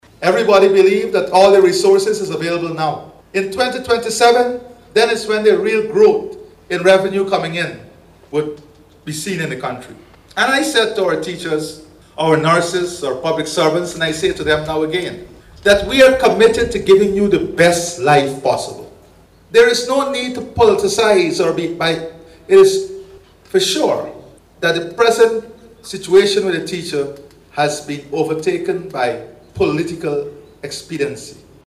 Speaking at the opening of the Guyana Technical Training College Facility Simulator in Port Mourant, President Ali assured all categories of workers that the government is dedicated to incrementally improving their working conditions.